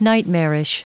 Prononciation du mot nightmarish en anglais (fichier audio)
Prononciation du mot : nightmarish